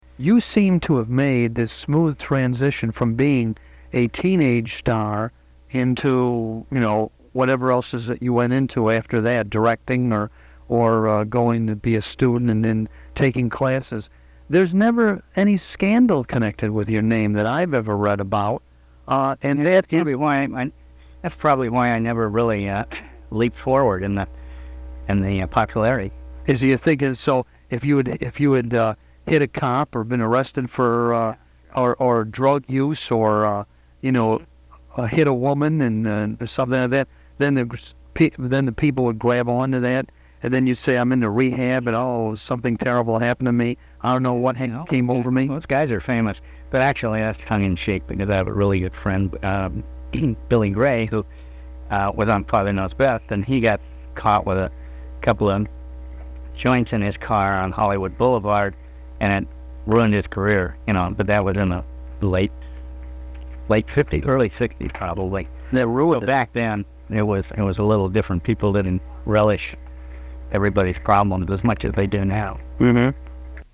Tony Dow Interview Producer, Director, Actor and Sculptor